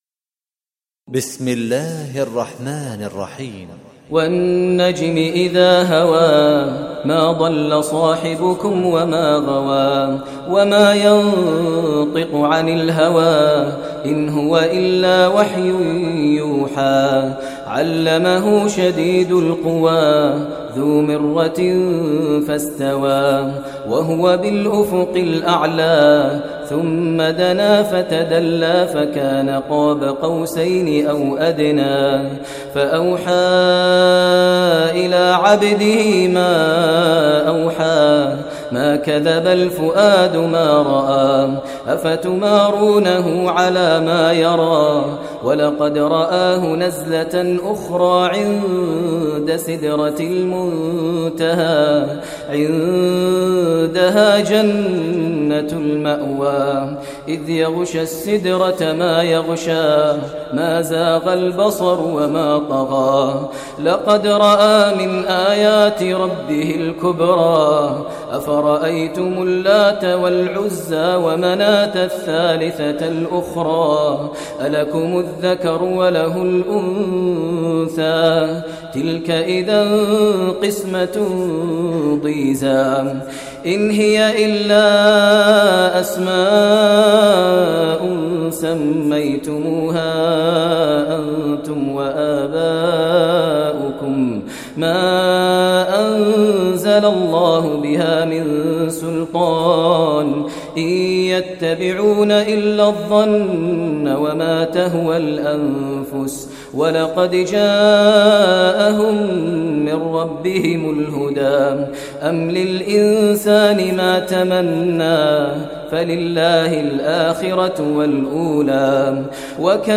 Surah Najm Recitation by Sheikh Maher Mueaqly
Surah Najm, listen online mp3 tilawat / recitation of Surah Najm in the voice of Sheikh Maher al Mueaqly. Surah Najm is 53 chapter of Holy Quran.